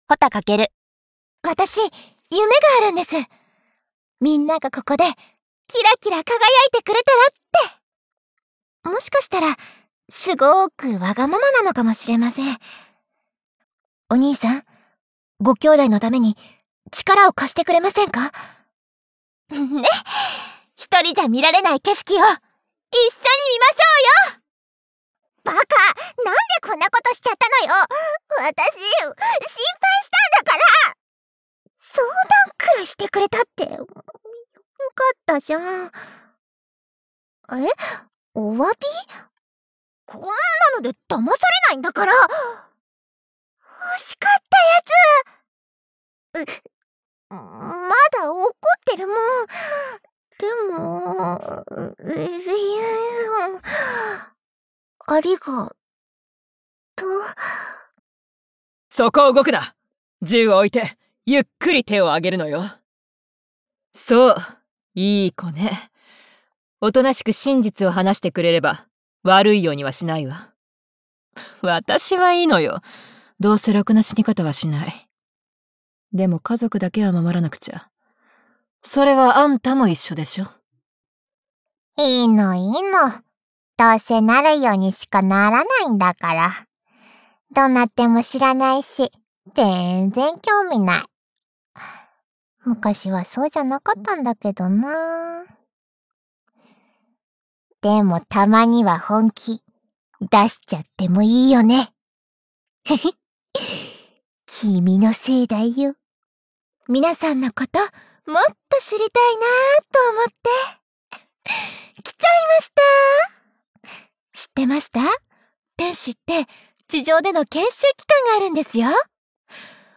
ボイスサンプル(mp3ほか)
ゲームCVCMナレ